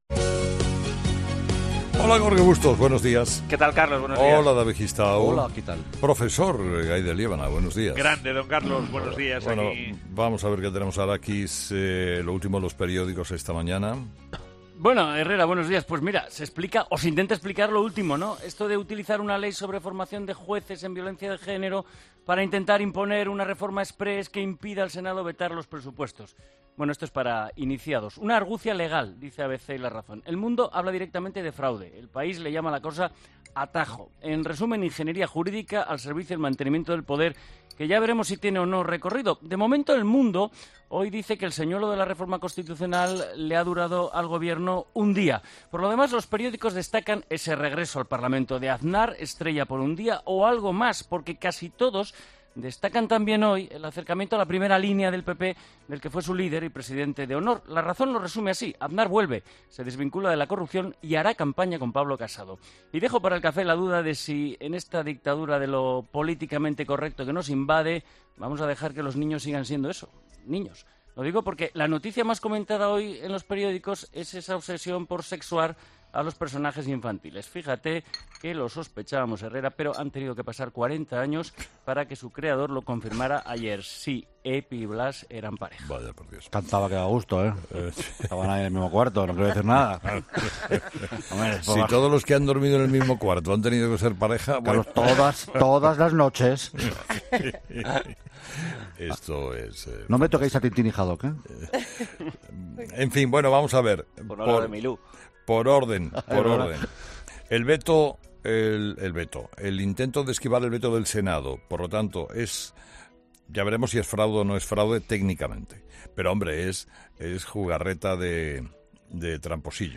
Revista de prensa en el primer café de la mañana en 'Herrera en COPE'